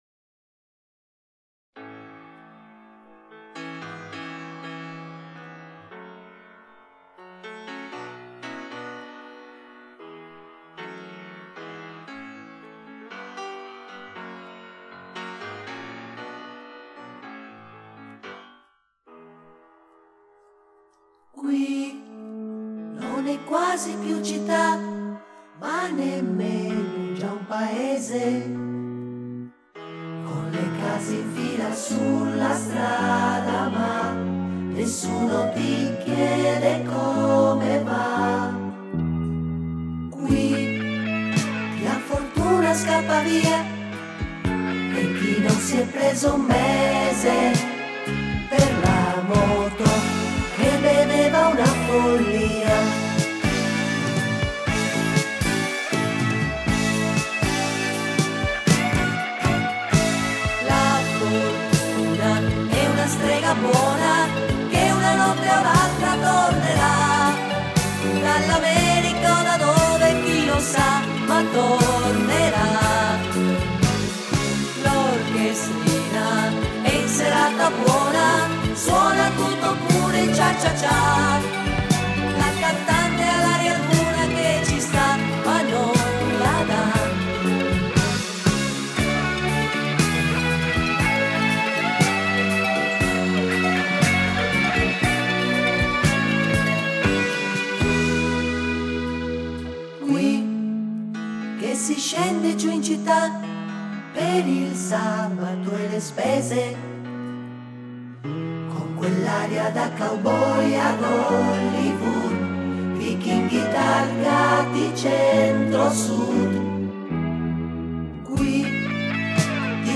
Итальянская эстрада